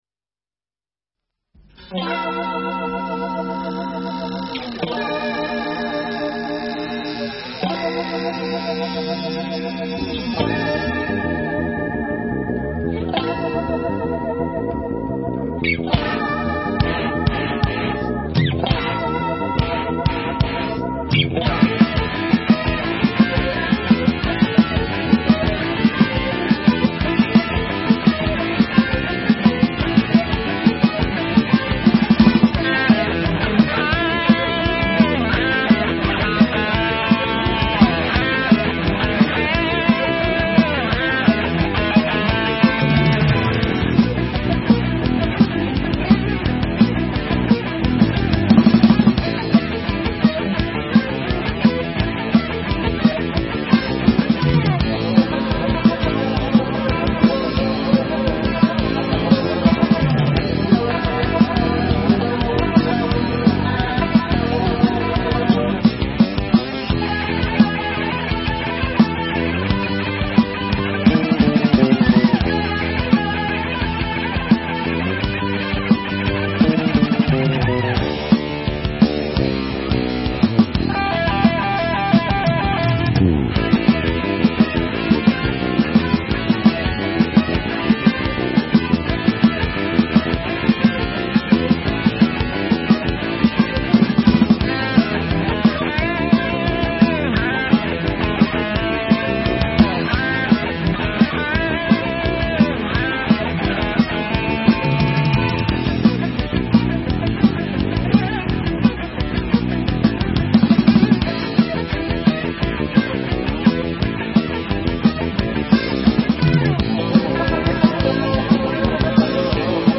纯音乐演奏